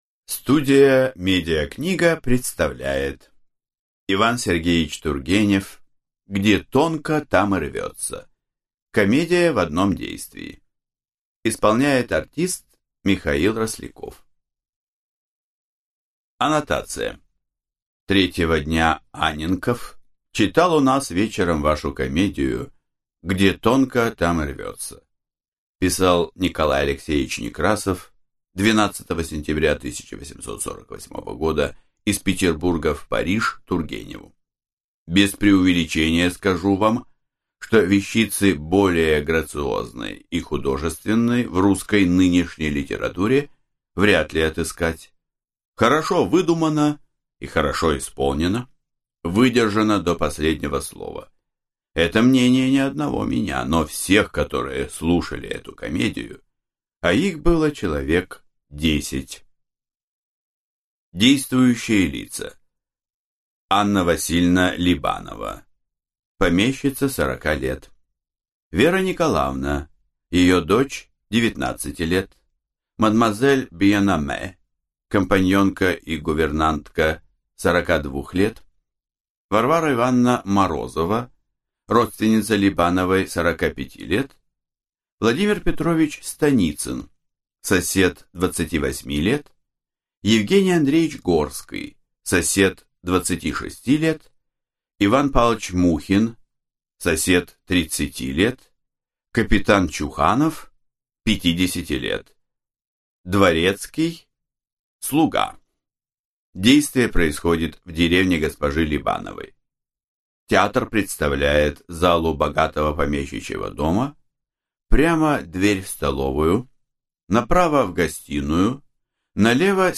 Аудиокнига Где тонко, там и рвется | Библиотека аудиокниг